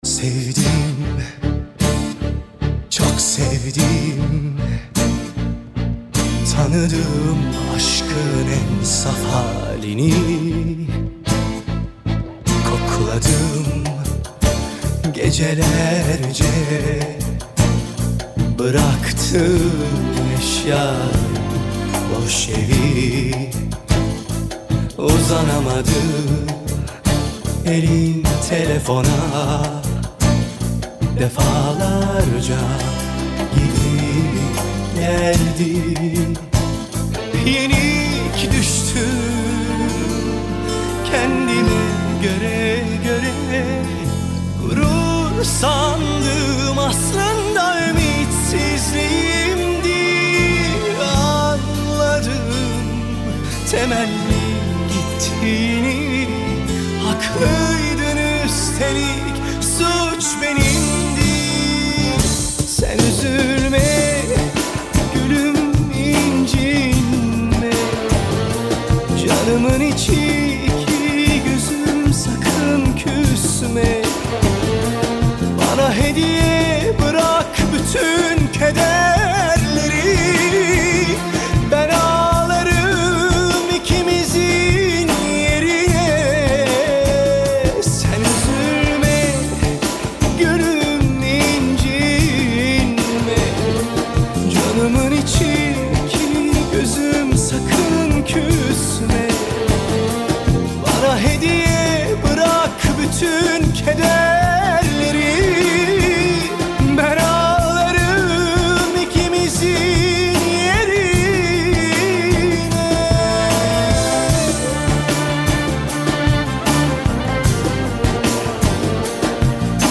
Файл в обменнике2 Myзыкa->Зарубежная эстрада